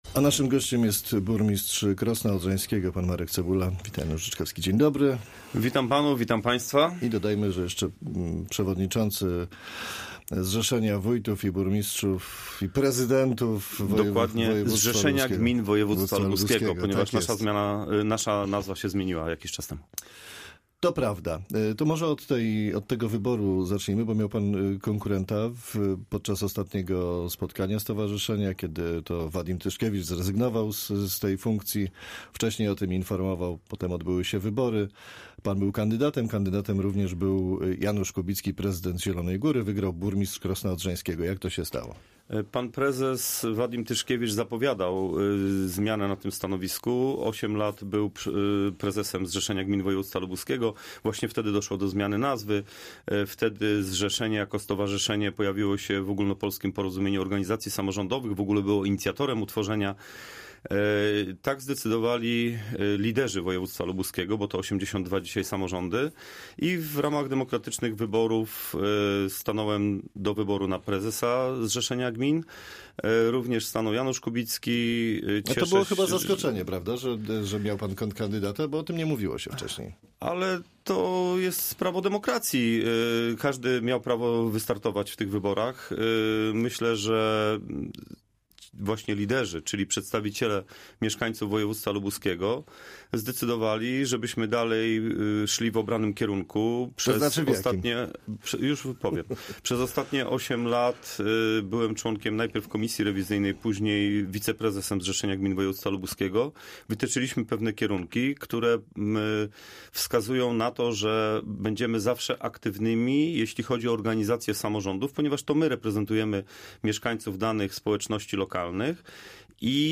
Z burmistrzem Krosna Odrzańskiego, przewodniczącym Zrzeszenia Gmin Województwa Lubuskiego rozmawia